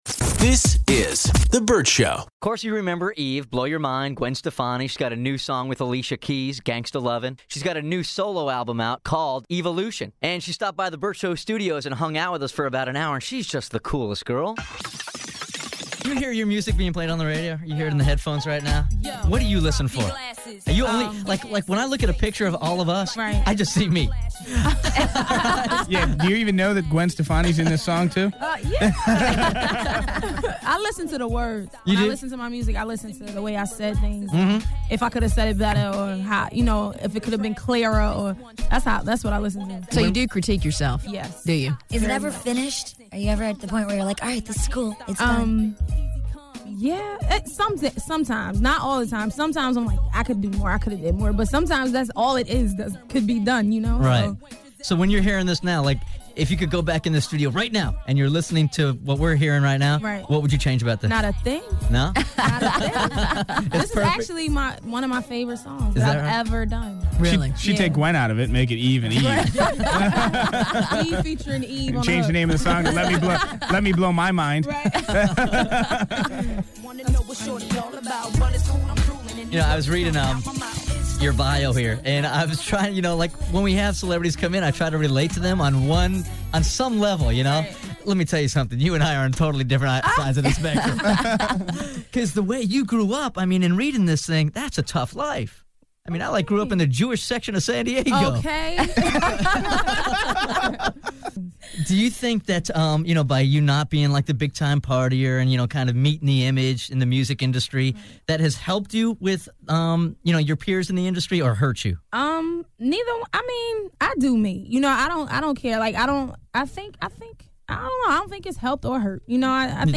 Vault: Interview With Eve